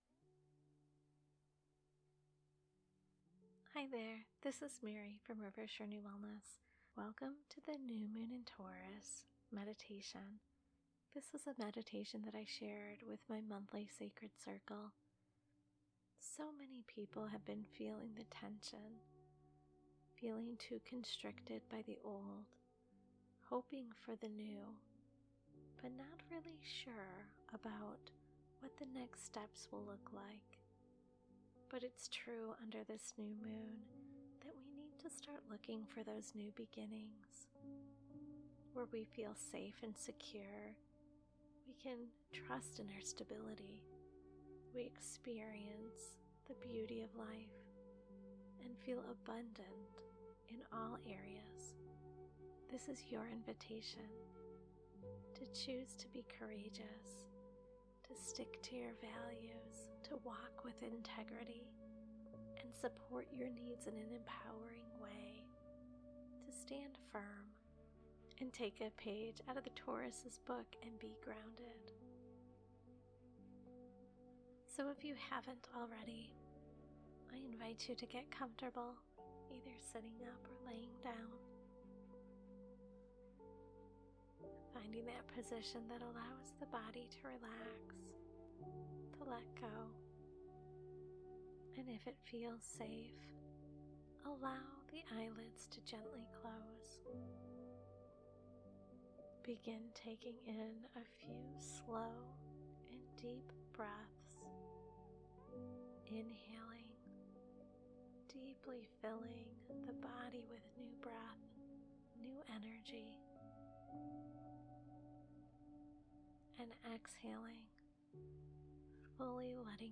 LISTEN TO NEW MOON IN TAURUS MEDITATION Listen to the Guided Meditations on Soundcloud or You Tube Happy Manifesting!